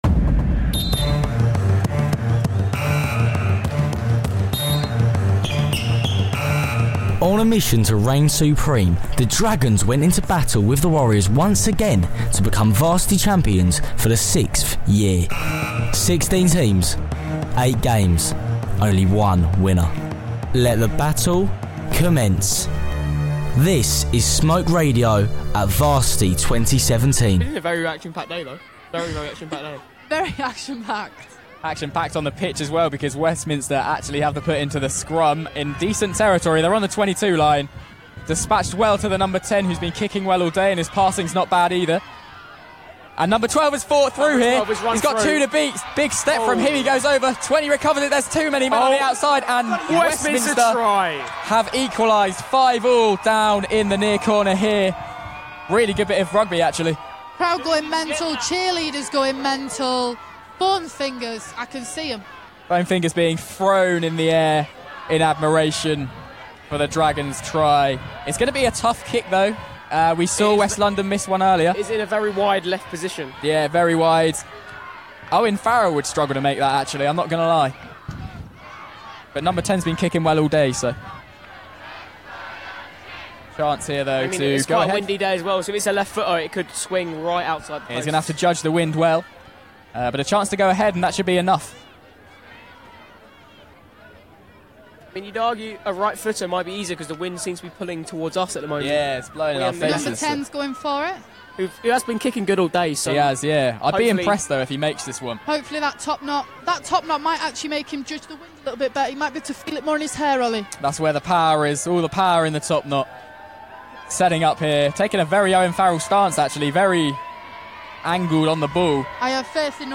Highlights from Smoke Live at Varsity 2017, giving you all the coverage you need from the Men’s Rugby. Including interviews with Men’s football and action from the aftermath of Varsity as Westminster take the crown for the 6th year in a row!